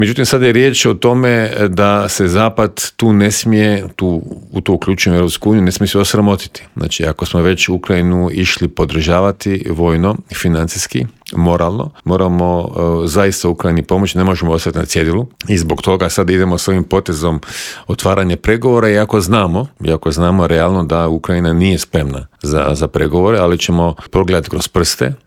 ZAGREB - Dok napetosti oko širenja Europske unije i nastavka pomoći Ukrajini traju, mađarski premijer Viktor Orban riskira i pokušava svojoj državi priskrbiti sredstva koja je Europska unija zamrznula, pojašnjava u Intervjuu Media servisa bivši inoministar Miro Kovač.
Mađarski premijer Viktor Orban smatra da Ukrajina ne ispunjava uvjete za otvaranje pregovora Europskoj uniji, a oko te odluke potreban je konsenzus svih članica Europske unije. "Orban želi da EU odblokira sav novac koji je zamrznut Mađarskoj", započinje bivši ministar vanjskih i europskih poslova Miro Kovač u Intervjuu Media servisa: